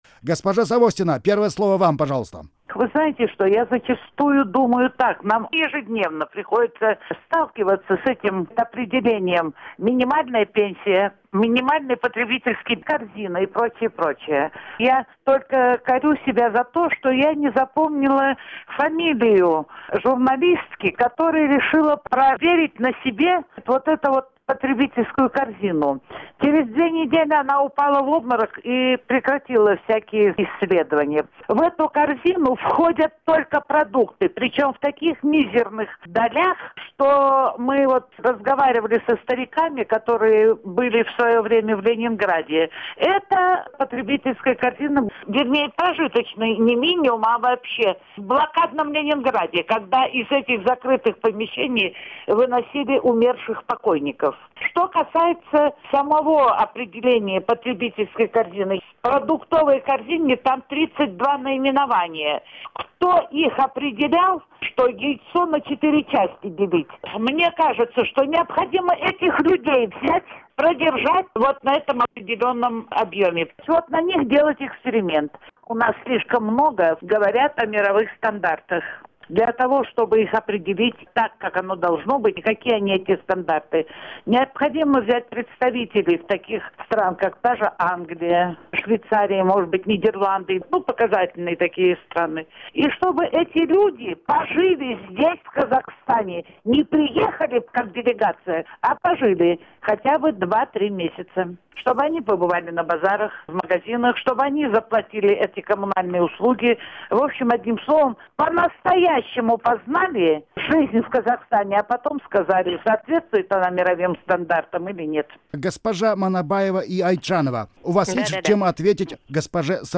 Аудиозапись круглого стола